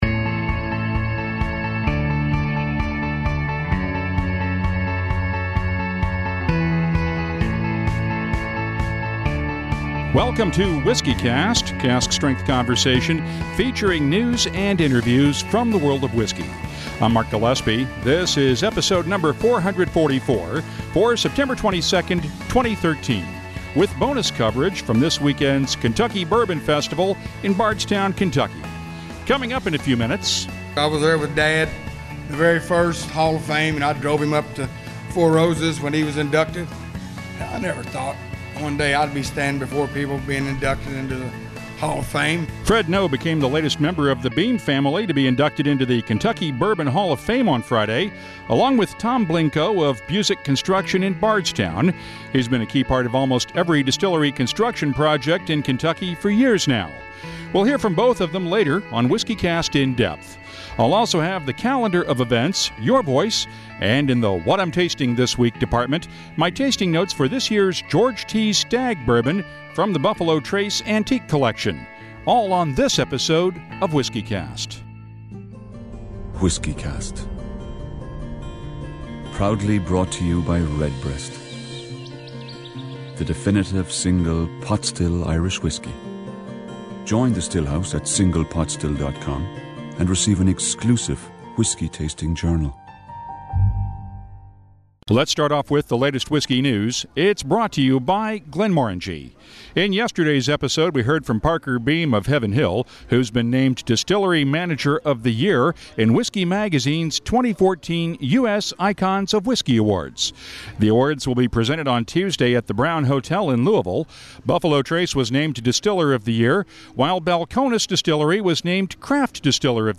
In the second of two episodes from this weekend's Kentucky Bourbon Festival in Bardstown, we'll hear from the newest members of the Kentucky Bourbon Hall of